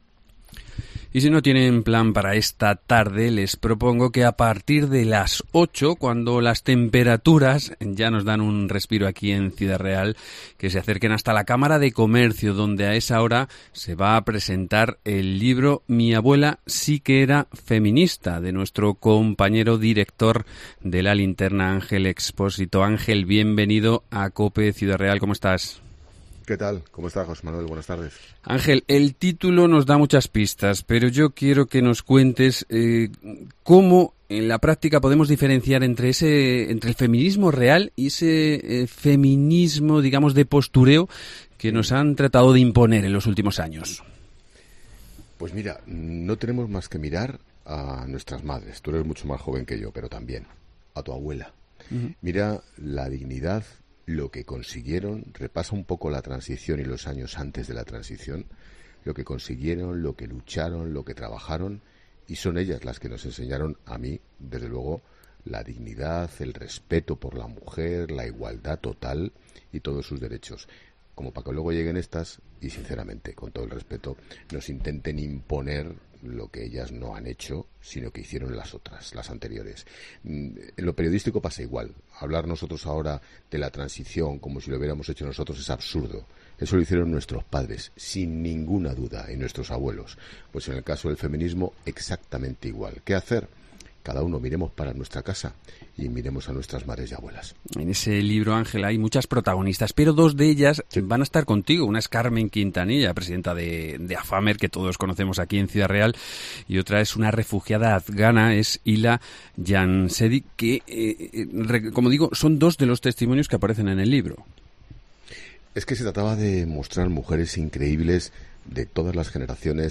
La Mañana de Ciudad Real, entrevista a Ángel Expósito